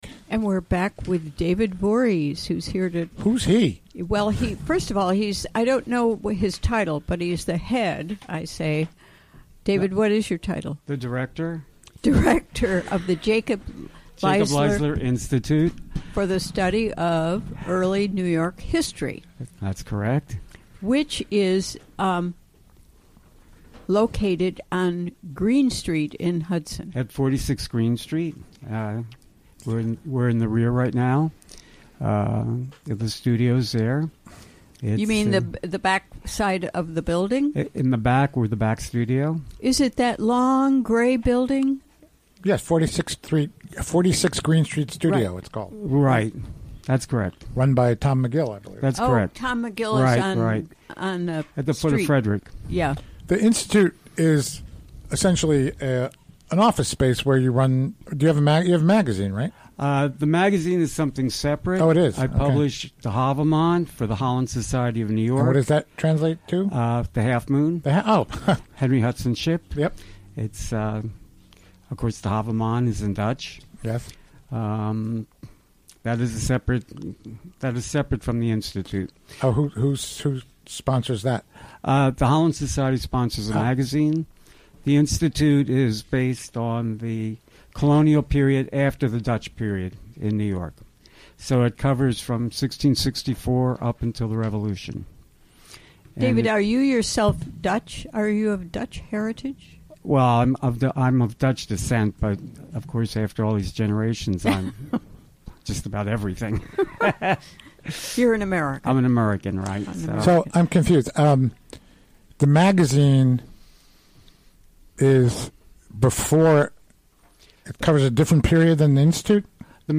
Recorded during the WGXC Afternoon Show Thursday, March 16, 2017.